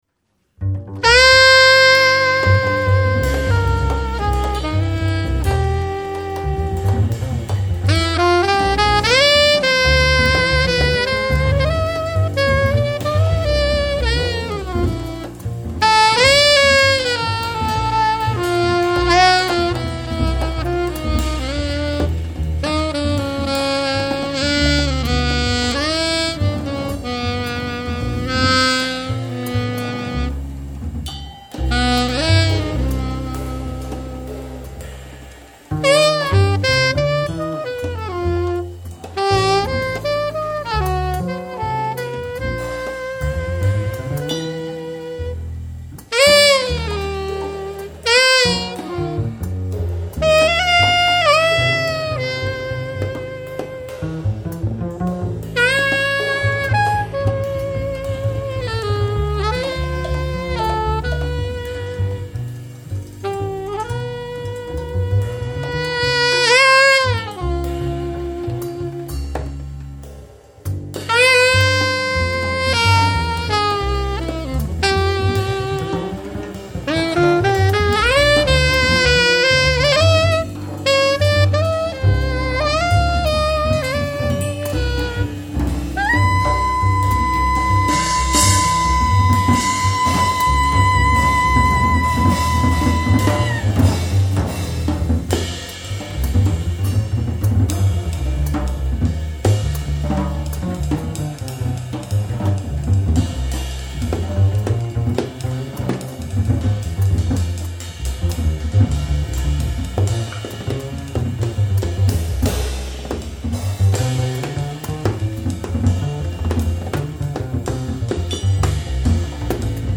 Stockholm, Sweden, August 25 & 26, 2004.